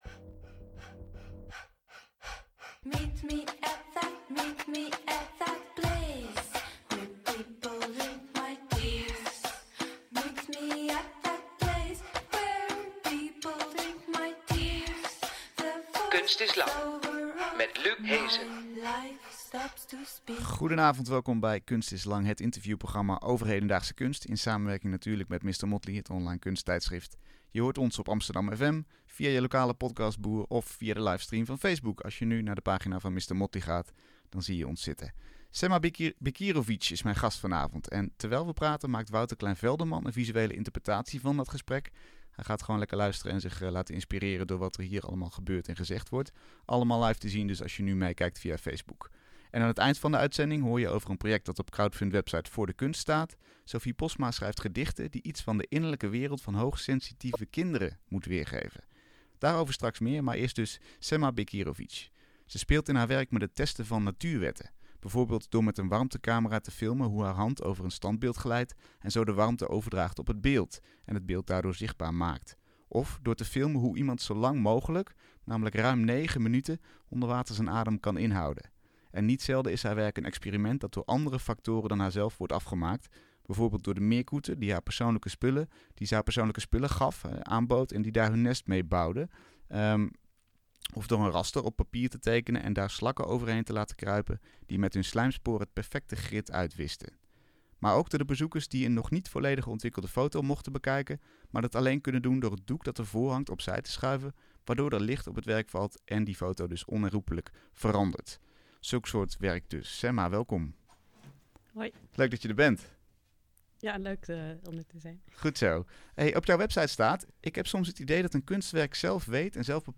Een gesprek over auteurschap, en oog hebben voor andere perspectieven.